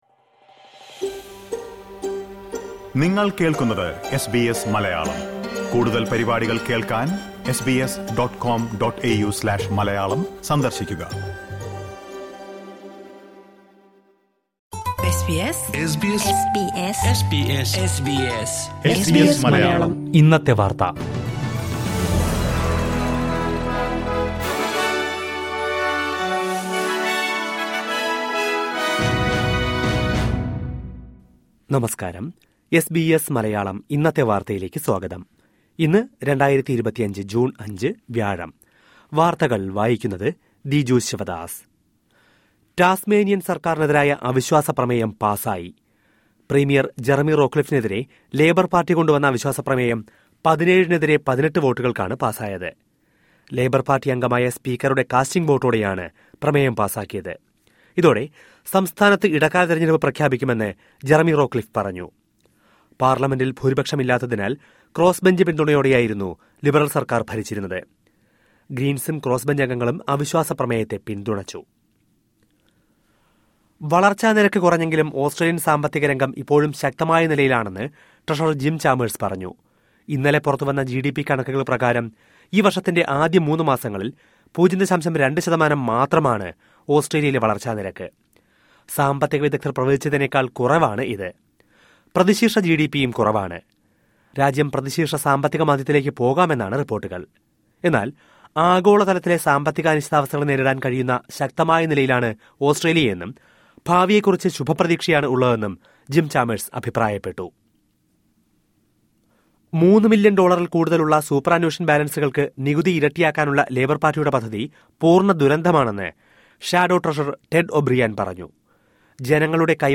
2025 ജൂണ്‍ അഞ്ചിലെ ഓസ്‌ട്രേലിയയിലെ ഏറ്റവും പ്രധാന വാര്‍ത്തകള്‍ കേള്‍ക്കാം.